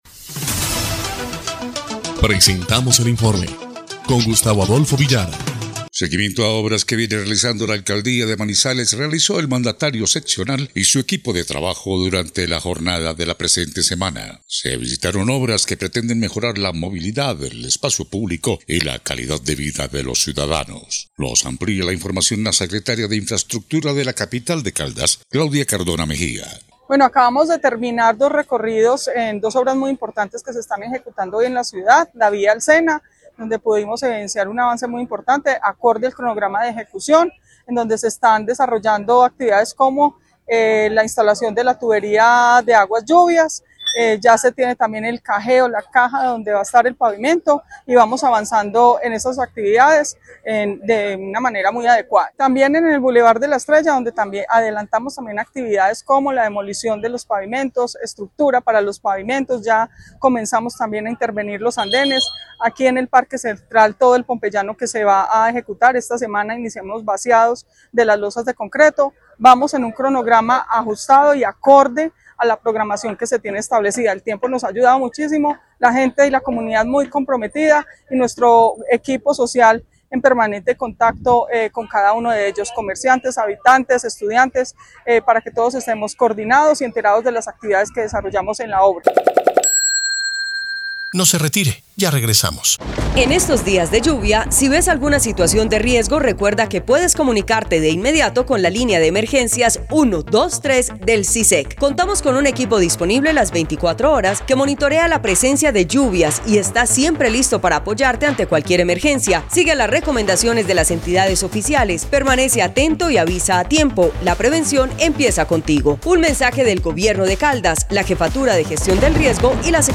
EL INFORME 1° Clip de Noticias del 20 de marzo de 2026